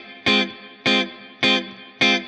DD_StratChop_105-Fmin.wav